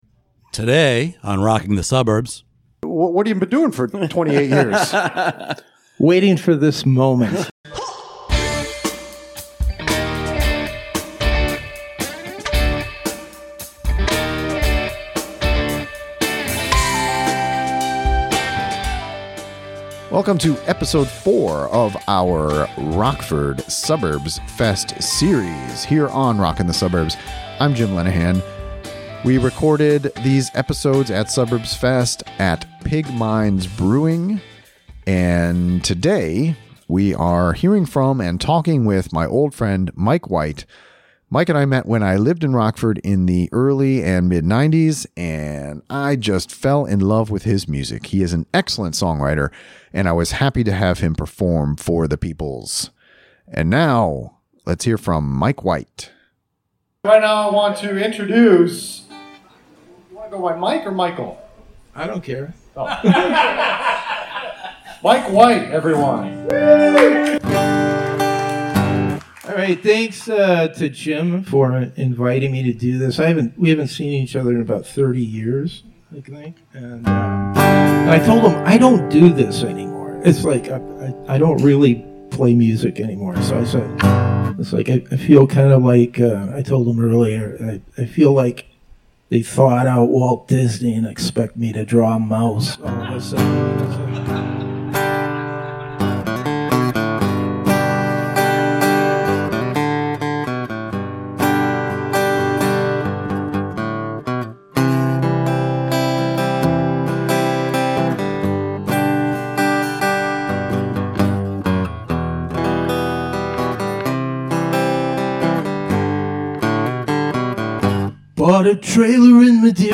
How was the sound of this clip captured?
Episode 4 from our Live from Suburbs Fest series. performed two songs at Pig Minds Brewing, then hung out for an interview